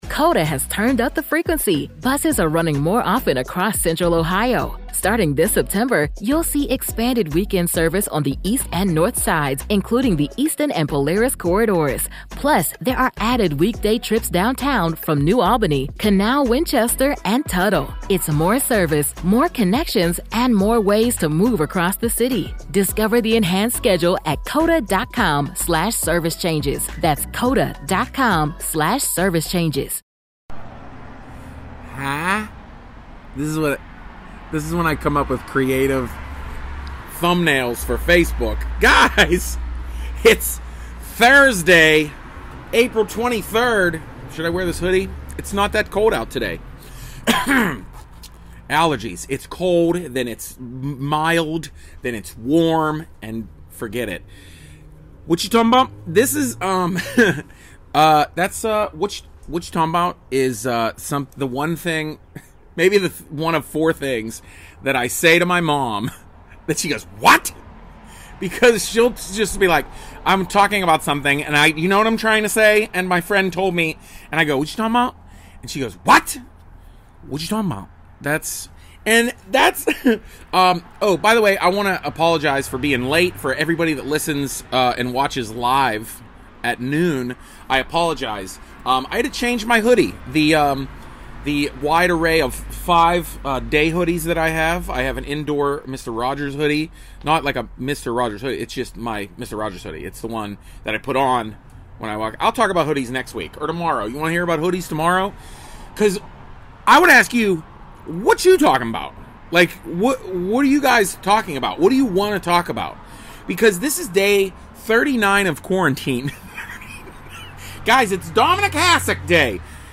On my porch pretty much in the morning drinking coffee.